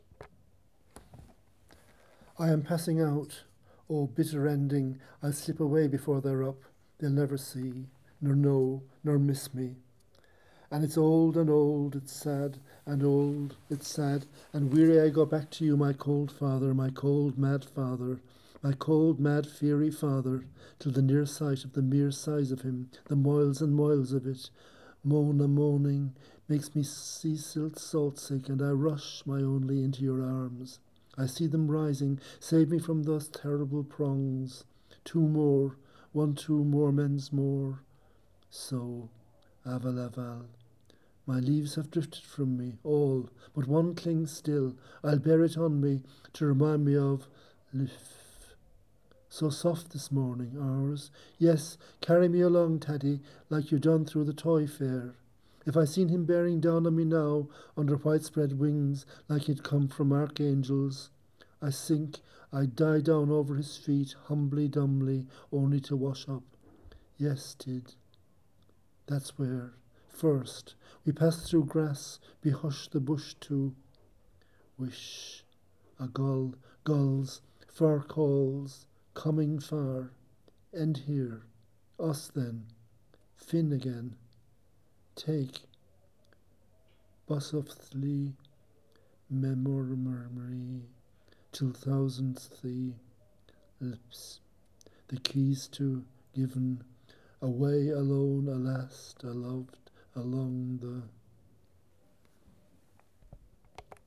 To hear the poet Bernard O’Donoghue reading the final lines of the Wake, press play